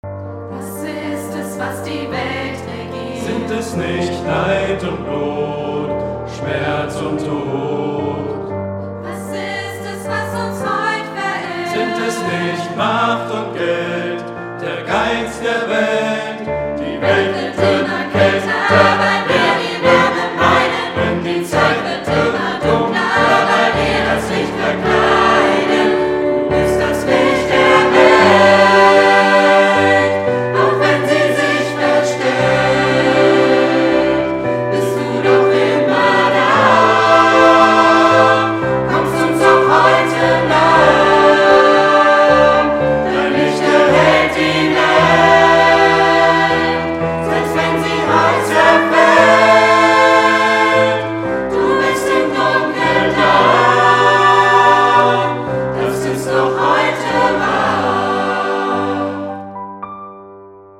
Notation: SATB
Tonart: Gm, G
Taktart: 4/4
Tempo: 112 bpm
Parts: 2 Verse, 2 Refrains, Bridge